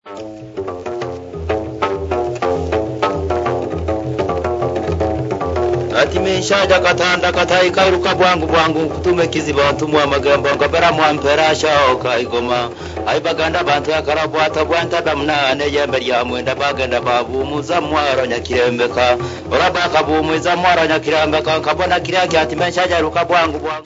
Folk music
Field recordings
sound recording-musical
The singer was word perfect throughout the whole recitation. He tells the locally well known legend of the Lake Victoria Nyanza.
The instrument had to be returned before the end of the legend, as one or two strings were slightly flat.
The Legend of spirit of the Lake, with Nanga trough Zither.
96000Hz 24Bit Stereo